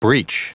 Prononciation du mot breech en anglais (fichier audio)
Prononciation du mot : breech